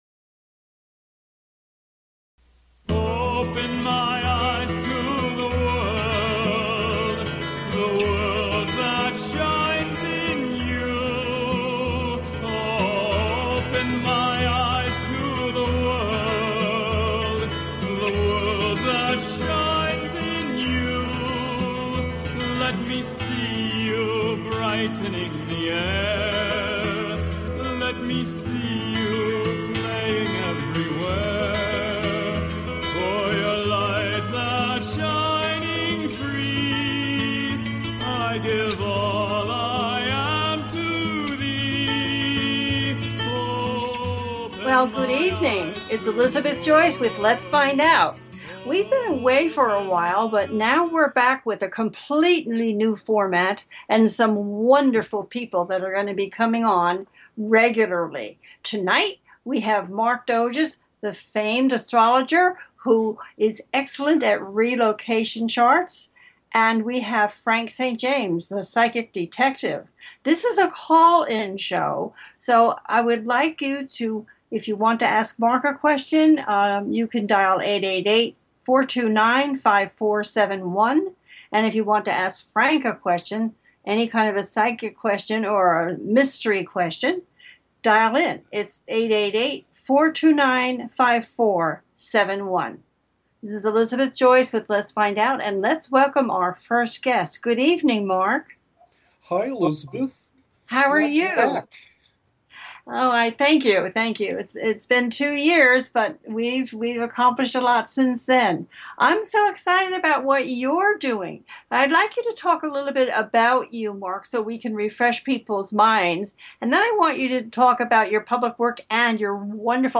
Talk Show Episode
Second guest - Psychic Detective